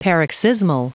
Prononciation du mot paroxysmal en anglais (fichier audio)
Prononciation du mot : paroxysmal